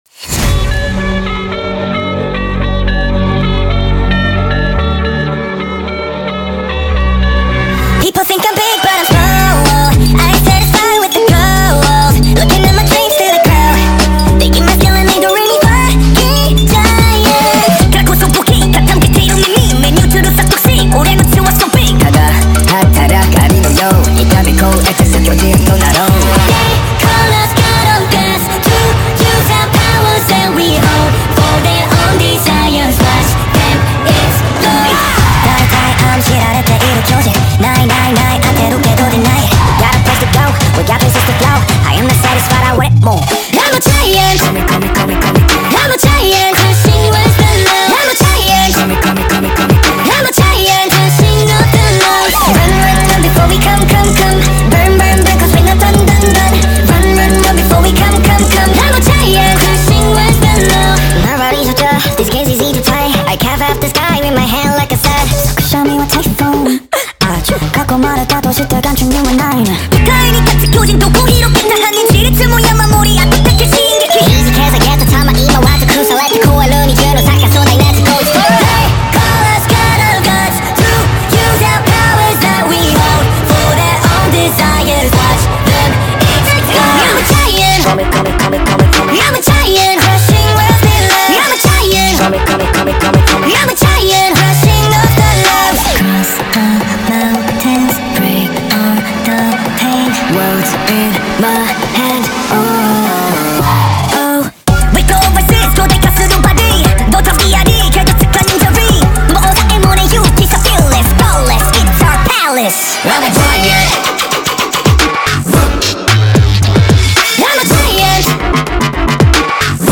Sped Up TikTok Remix